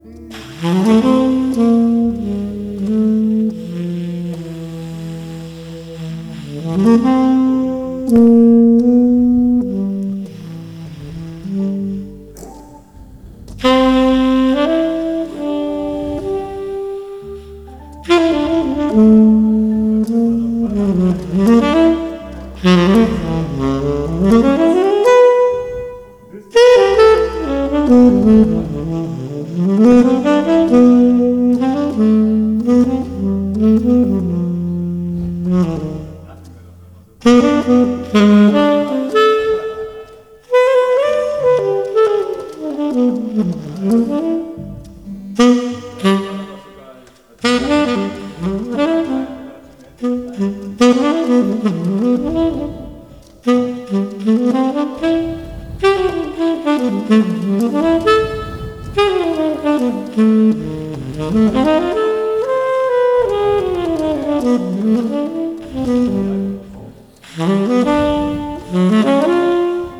Jazz - Organ with Sax
Melodic Saxophone.